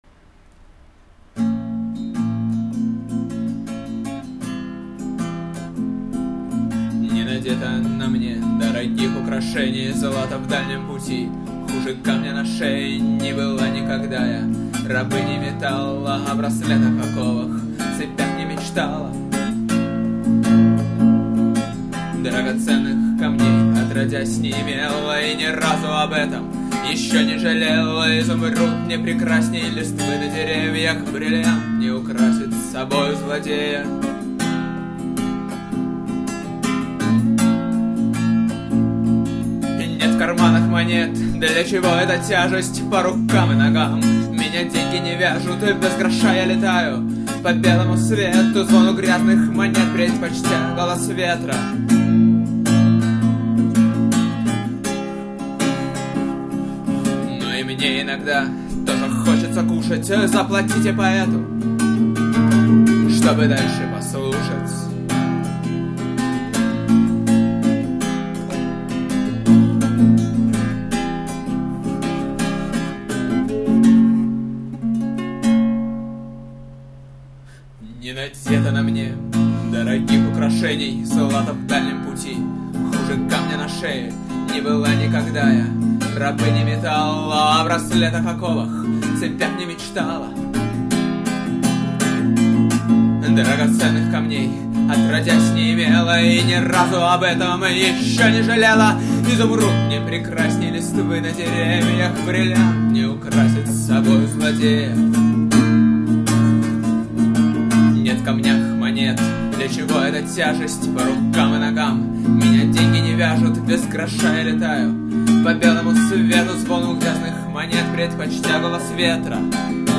Потом появилось два исполнения.